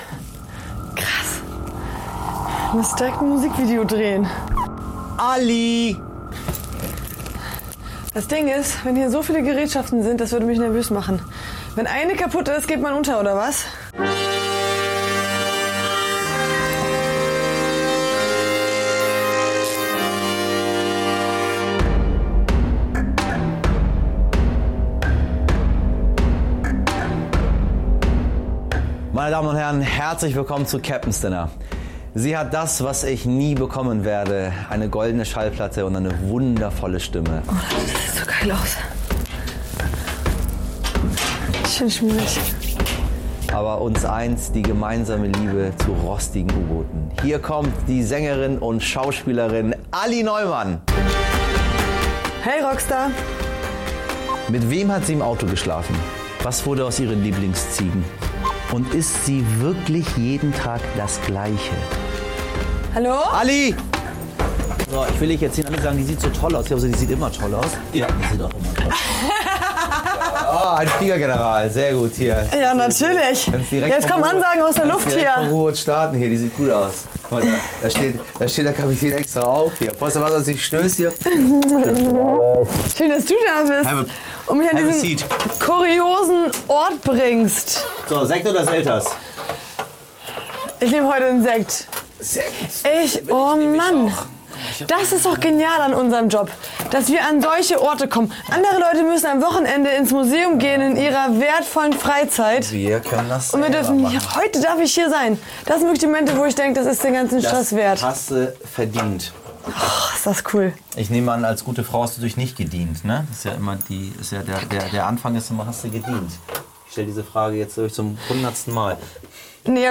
Bei Michel Abdollahi im U-Boot ist Sängerin Alli Neumann zu Gast.